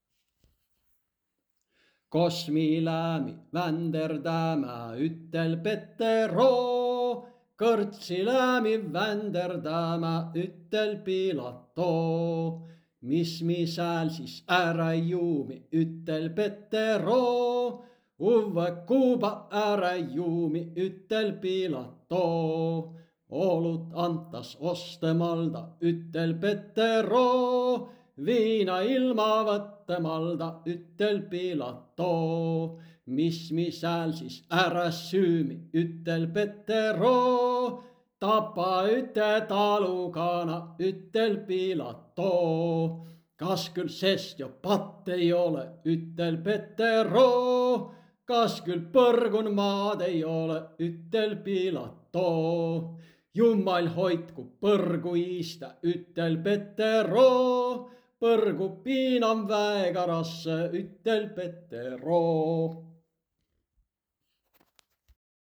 Q-häälduse puhul võib abi olla järgmisest kahest näitest:
Laulus kuuldub see nõrgemini kui kõnes ja selle puudumine ei mõjuta žürii hinnangut töötlusele.